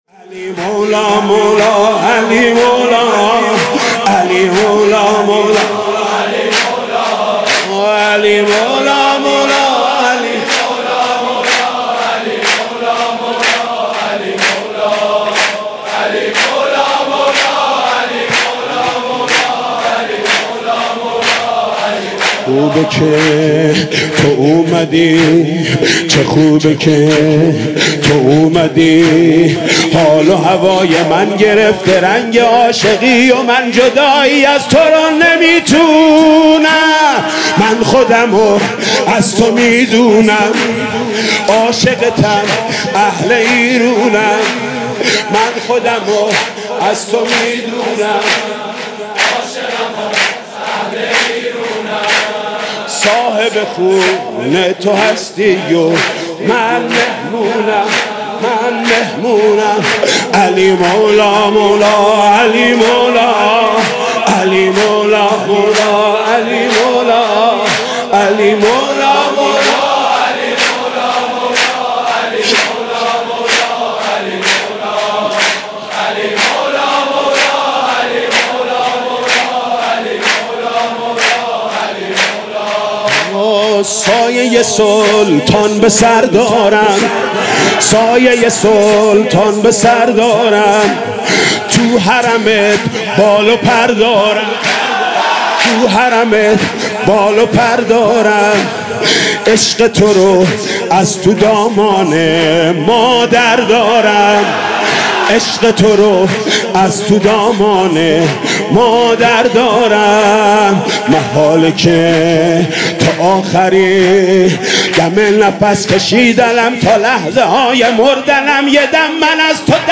«میلاد امام رضا 1395» سرود: چه خوبه که تو اومدی